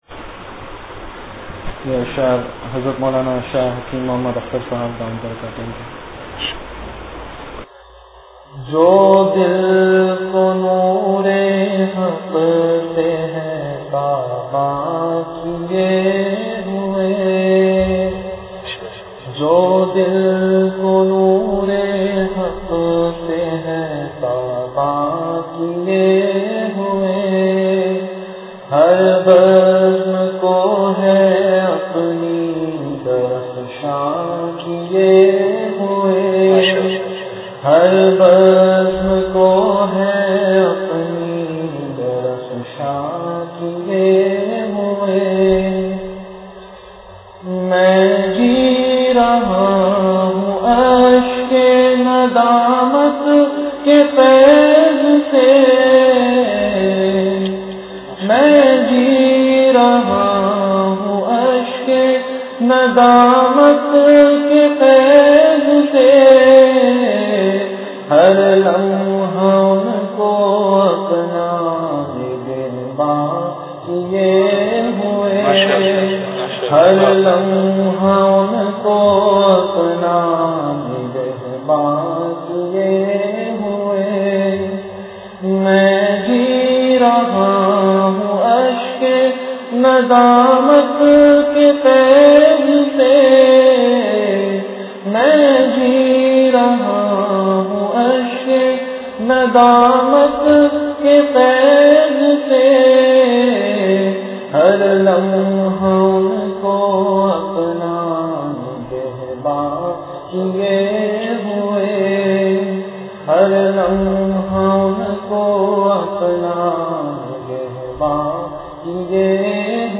Delivered at Home.
Majlis-e-Zikr
After Isha Prayer